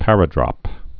(părə-drŏp)